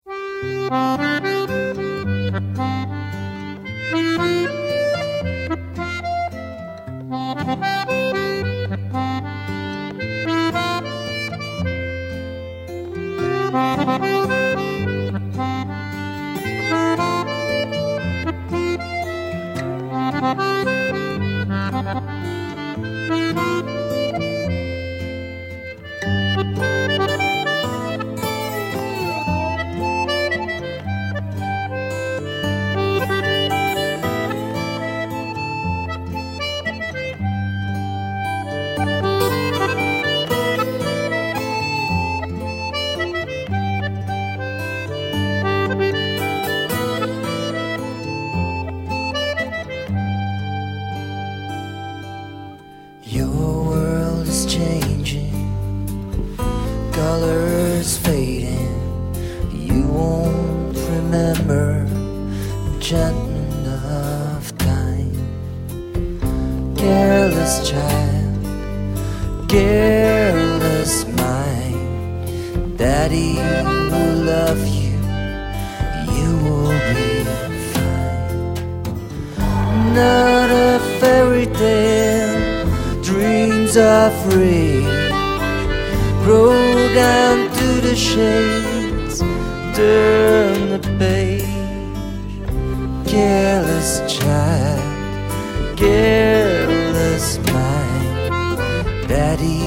valse